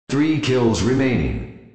Announcer